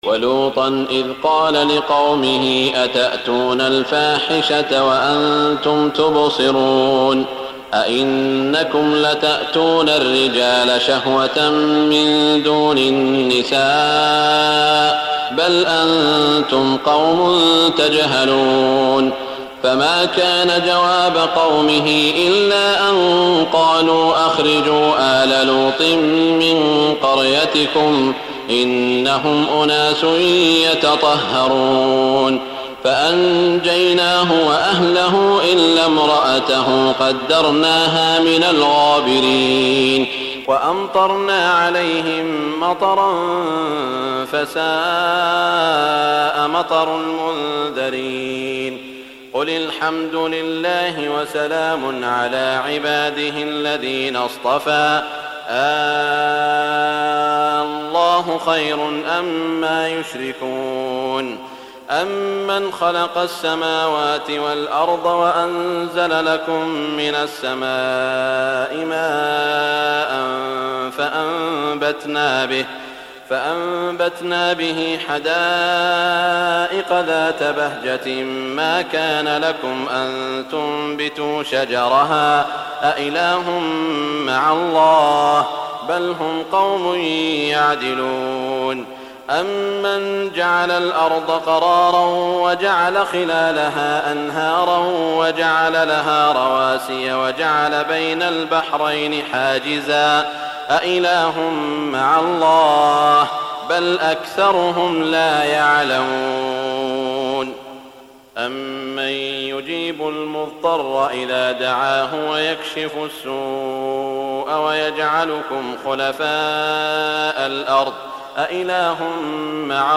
تراويح الليلة التاسعة عشر رمضان 1418هـ من سورتي النمل (54-93) و القصص (1-50) Taraweeh 19 st night Ramadan 1418H from Surah An-Naml and Al-Qasas > تراويح الحرم المكي عام 1418 🕋 > التراويح - تلاوات الحرمين